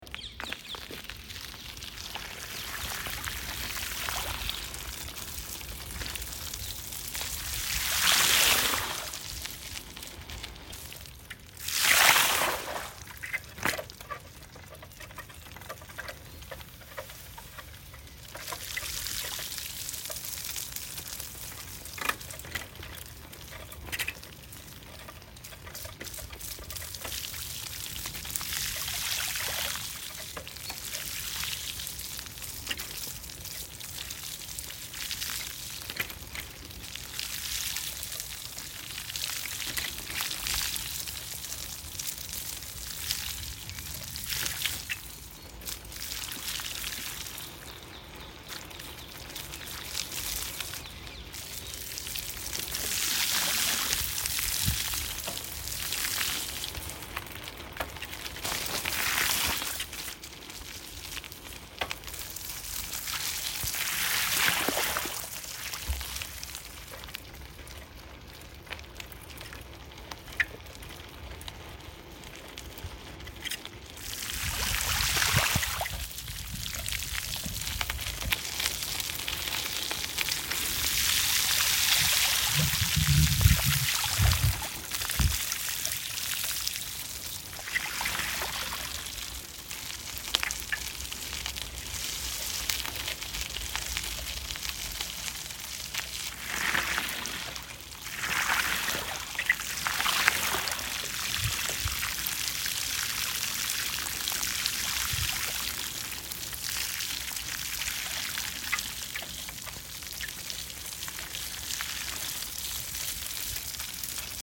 Разные велосипедные звуки: колеса и трещотки велосипедные, езда на велосипеде, звонок, тормоза, цепи.
1. Звук езды велосипеда по слякоти и лужам
ezda-na-velosipede-po-lujam-1.mp3